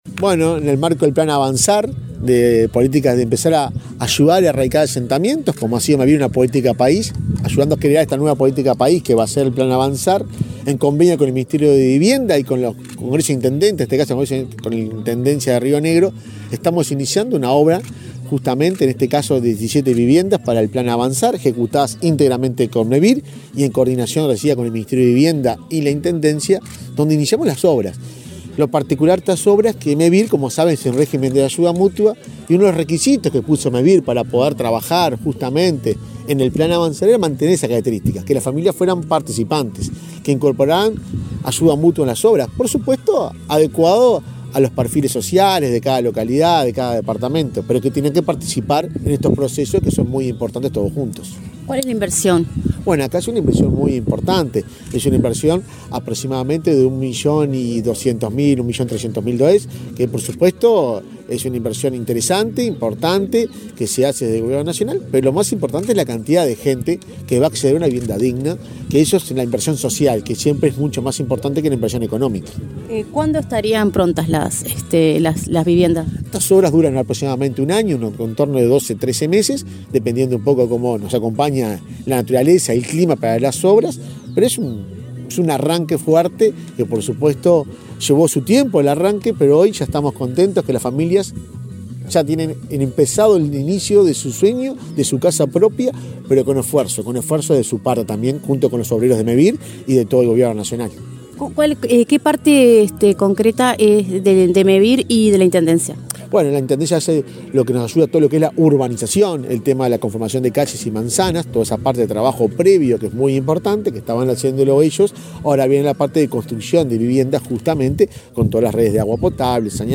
Entrevista al presidente de Mevir, Juan Pablo Delgado
El presidente de Mevir, Juan Pablo Delgado, dialogó con Comunicación Presidencial en Fray Bentos, antes de participar del lanzamiento de un programa